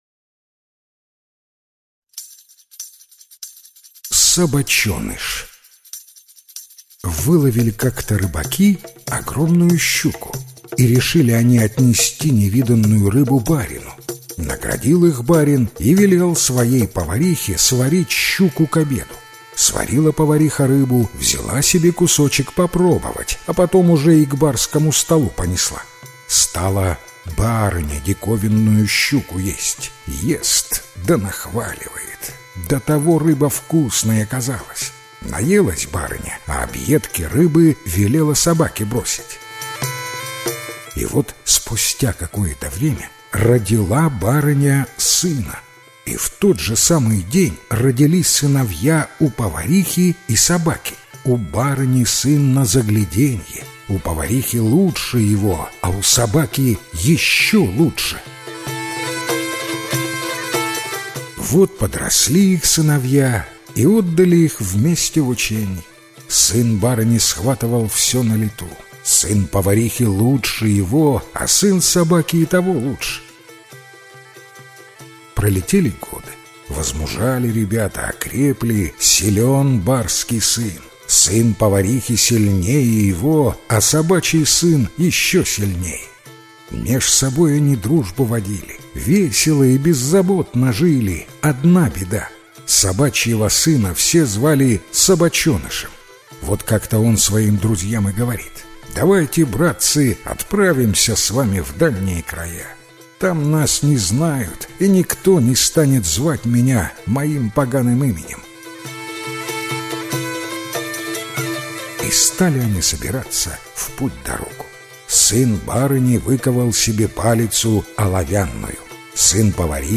Собачоныш - белорусская аудиосказка- слушать онлайн